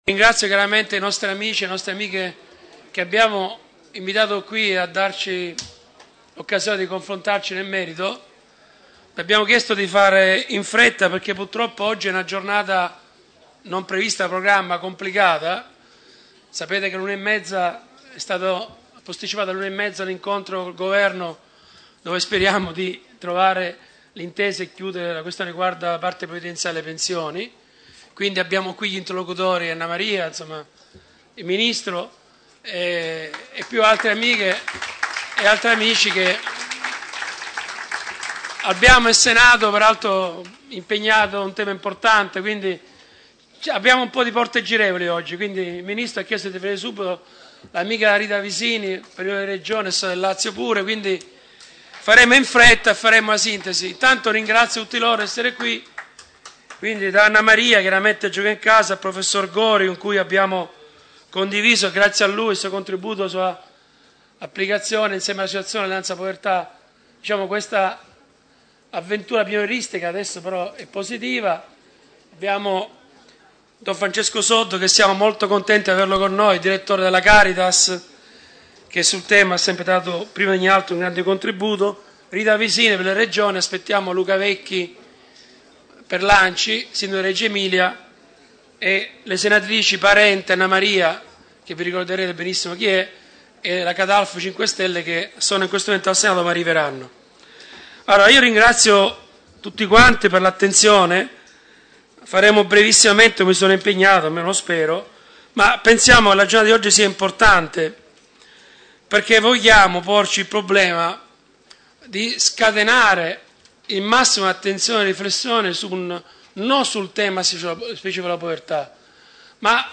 Povertà e Welfare dell’inclusione. Se ne discute a Roma in un dibattito politico organizzato dalla Cisl